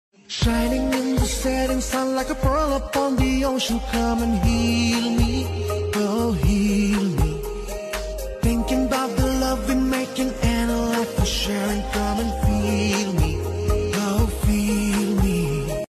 Mind Relaxing song